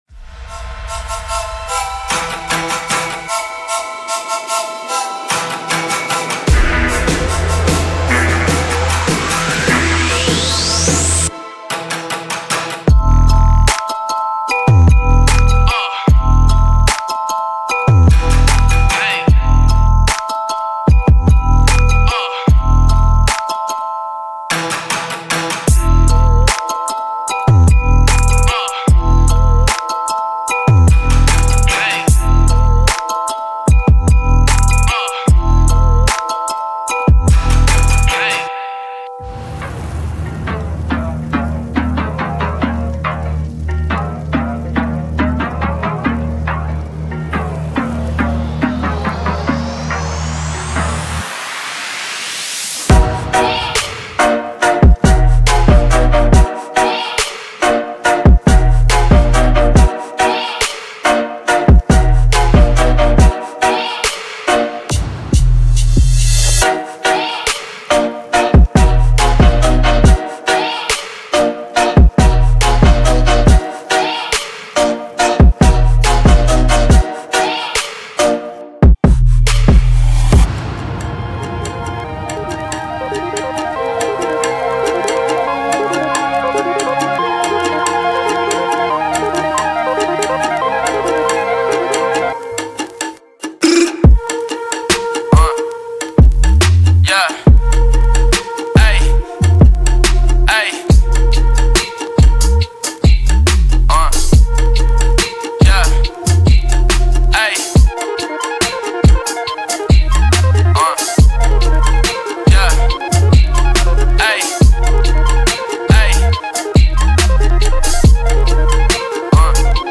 Hip HopTrap
鼓-打击乐器
-军鼓
-声乐诗歌
-嗨帽